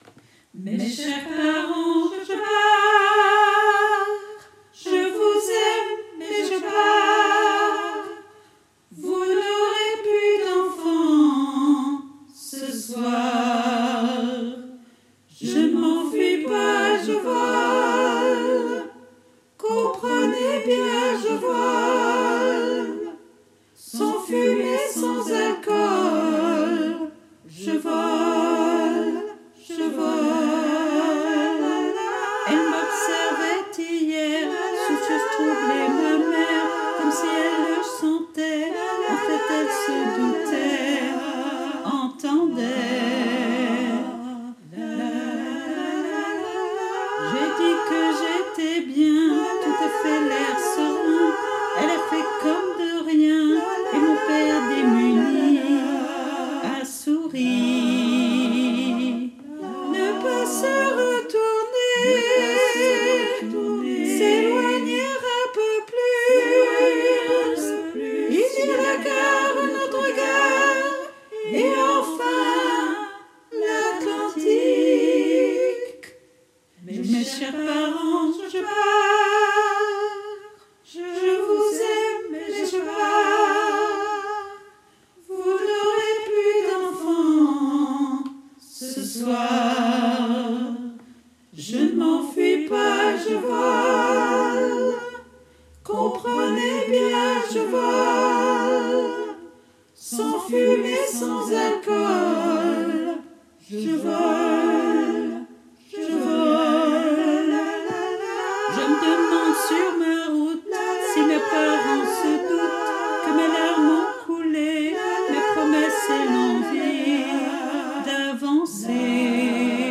MP3 versions chantées
Version chantée - 4 voix